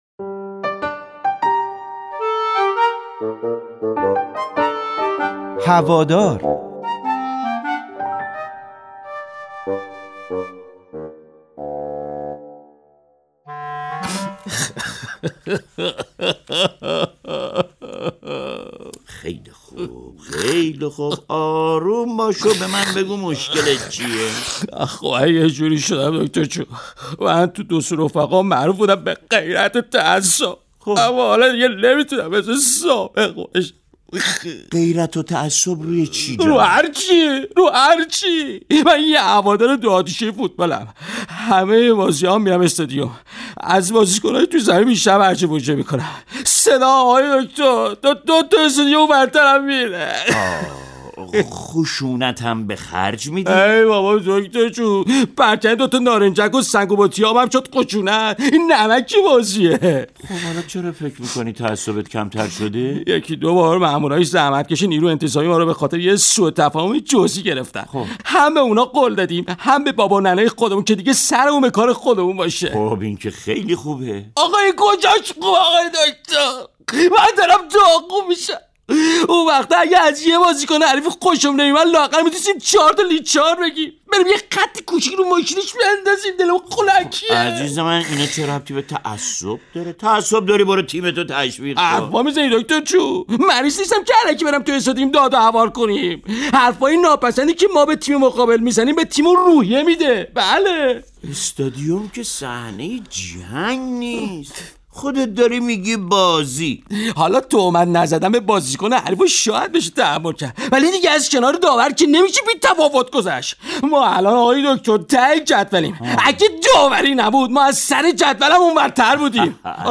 به گزارش خبرنگار مهر، نمایش رادیویی «هوادار» درباره فردی است که هوادار متعصبی است ولی خیلی وقت است نتوانسته به شکل متعصبانه ای از تیم مورد علاقه اش طرفداری کند و به طور مثال صندلی بشکند و یا به تیم مقابل ناسزا بگوید.
/مردی در حال گریه است و صدای هق هق اش می آید.